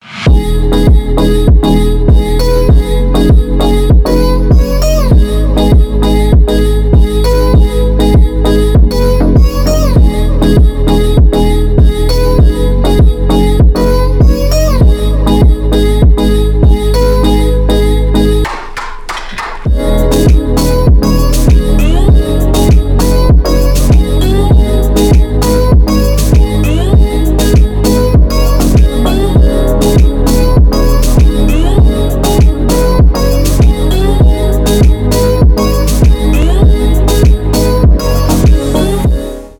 deep house
битовые , зарубежные , танцевальные
electronic